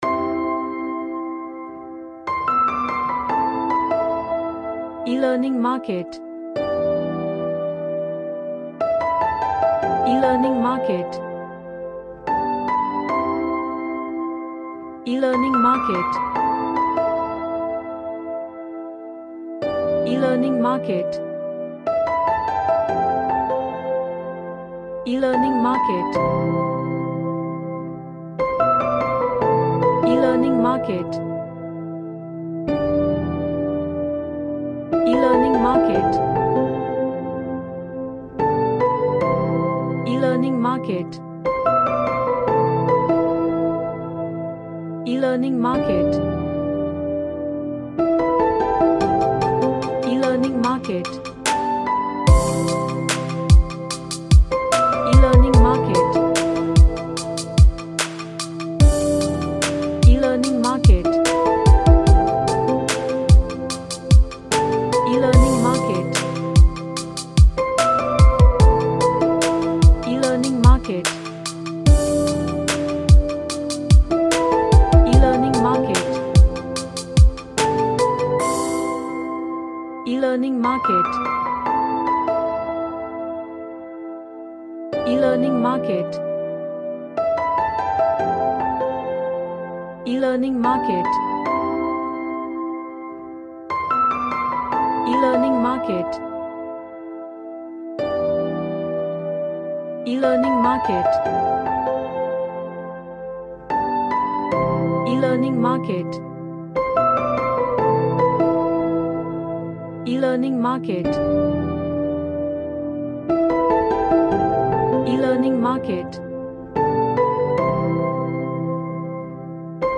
A hybrid of indie and R&B music
Sad / Nostalgic